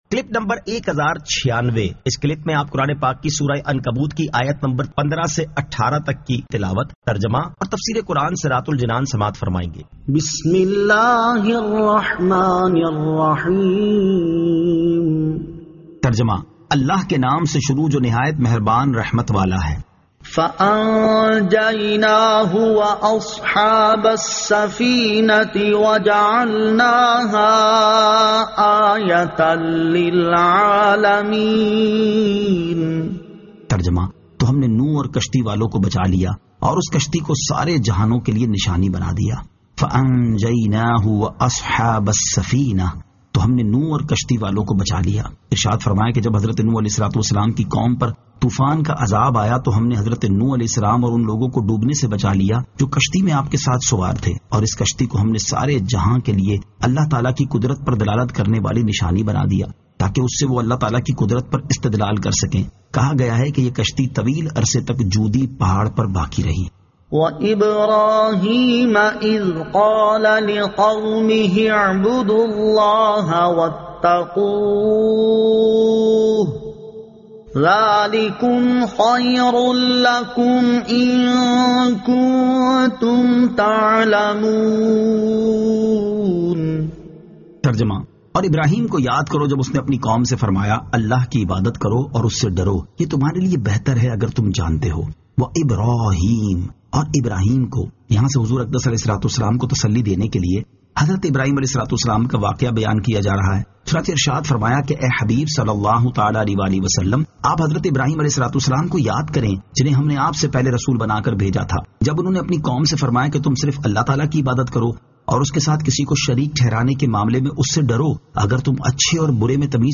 Surah Al-Ankabut 15 To 18 Tilawat , Tarjama , Tafseer